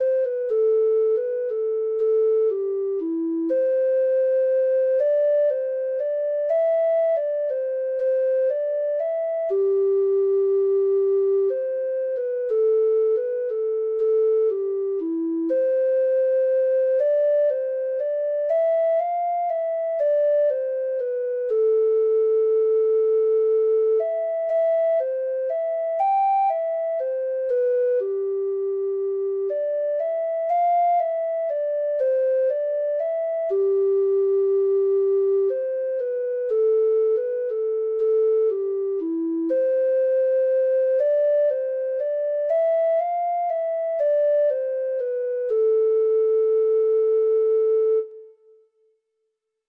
Traditional Trad. ONE WIFE IS ENOUGH FOR ME (Irish Folk Song) (Ireland) Treble Clef Instrument version
Irish